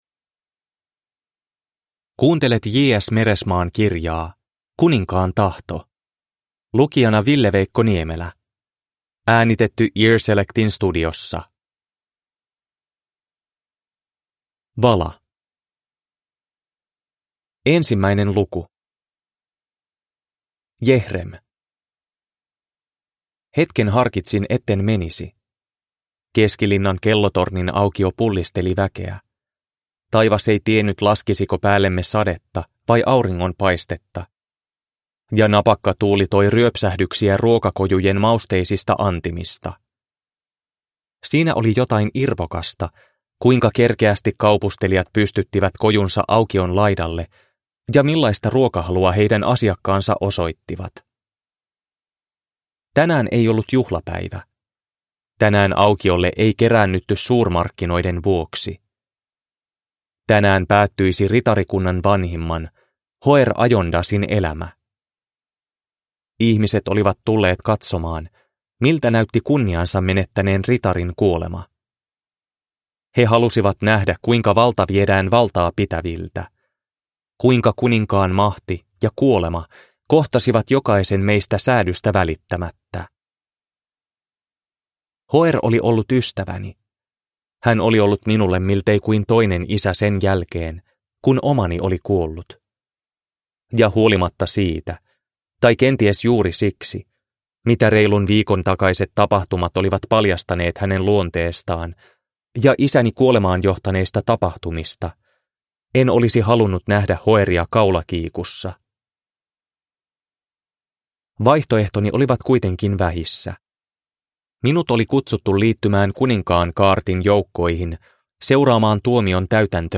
Kuninkaan tahto – Ljudbok